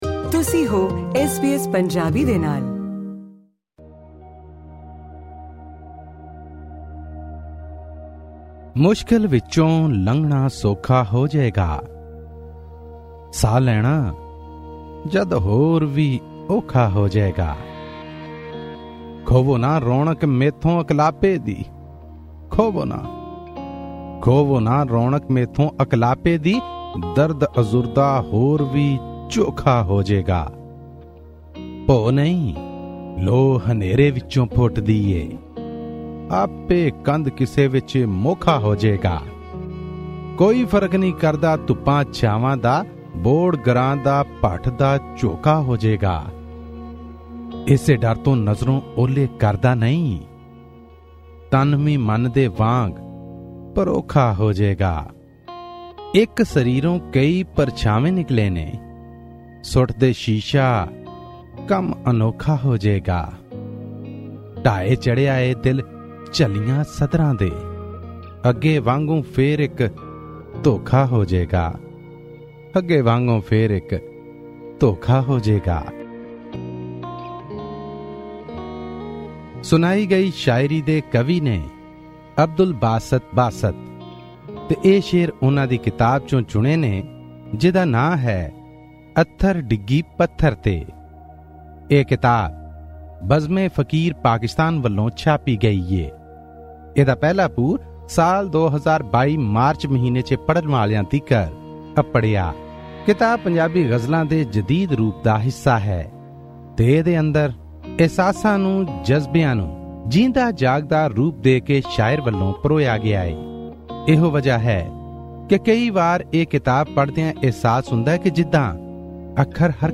Book review